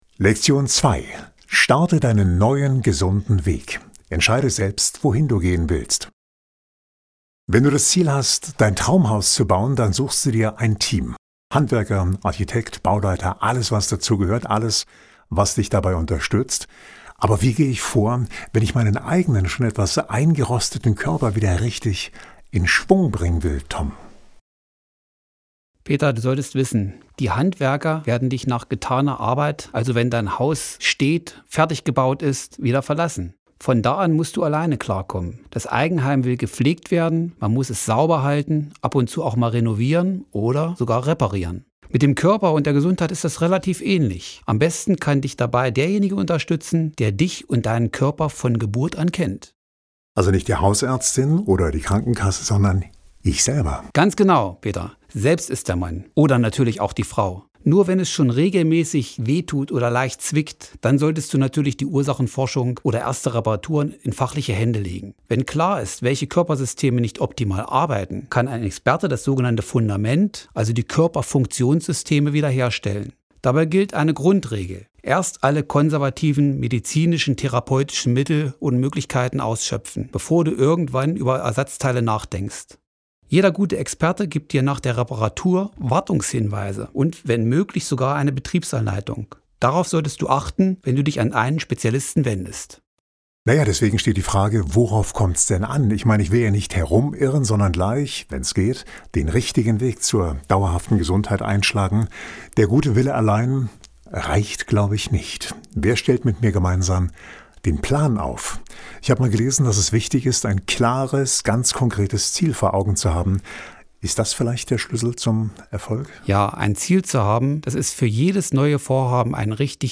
Dialog